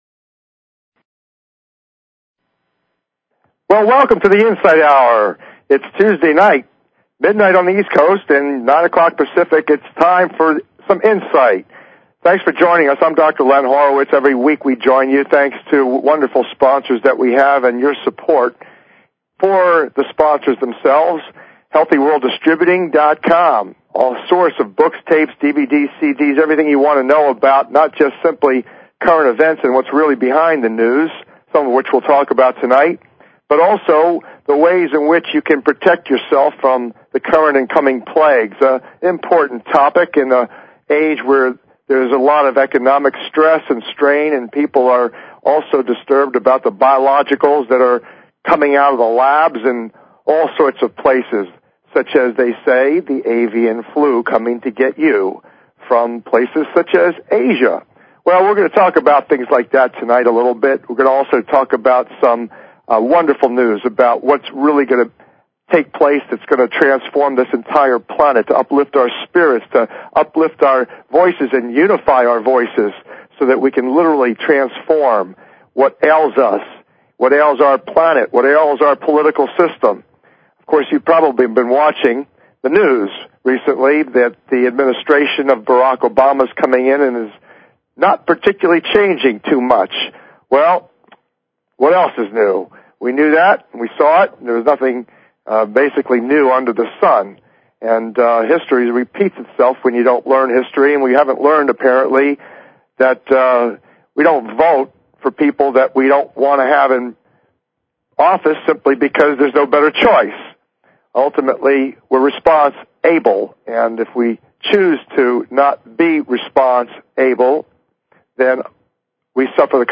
Talk Show Episode, Audio Podcast, The_Insight_Hour and Courtesy of BBS Radio on , show guests , about , categorized as